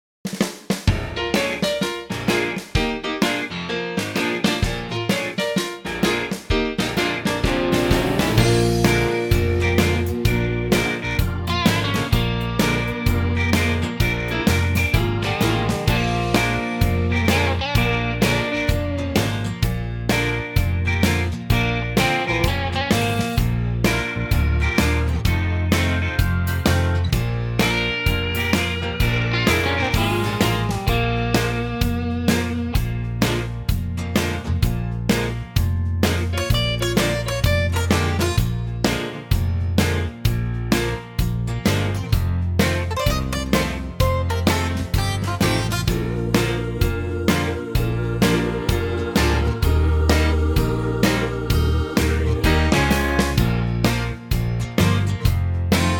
8 bar intro and vocal in at 15 seconds
key changes included, and a definite finish.
key - Bb to B to C - vocal range - F to G
-Unique Backing Track Downloads